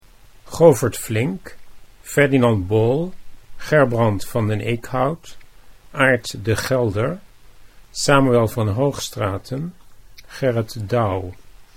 Pronunication Guide to Dutch words Concering the Life and Work of Rembrandt van Rijn